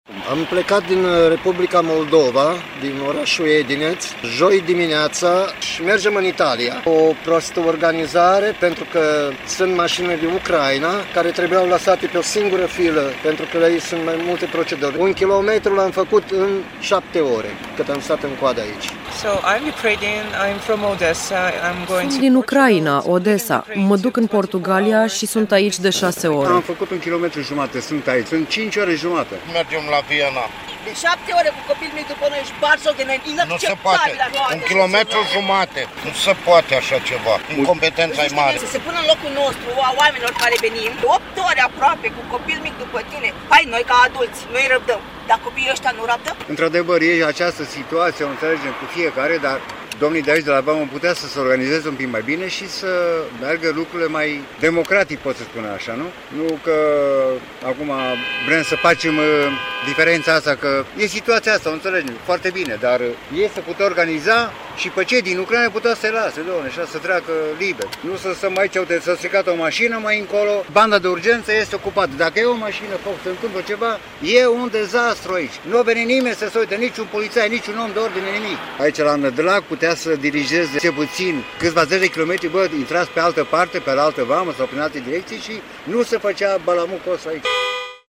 a fost în Vama Nădlac 2 și a discutat cu cei care stau la coadă